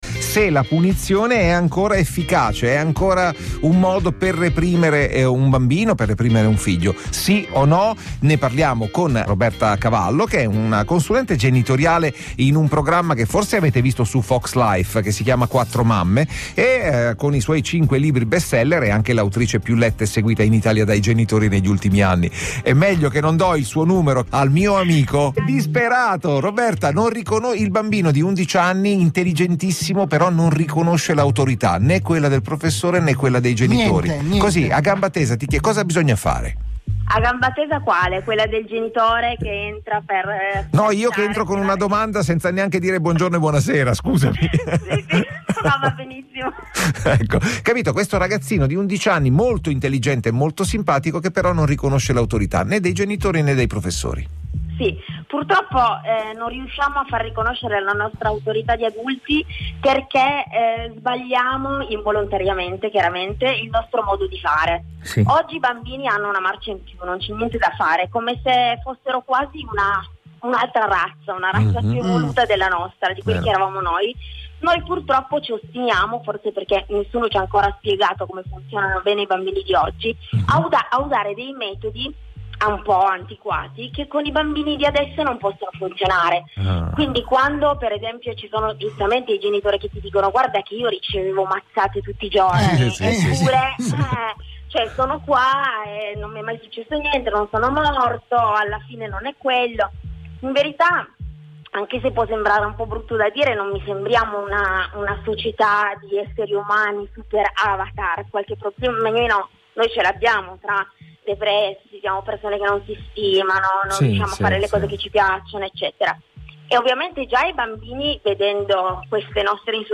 INTERVISTE RADIO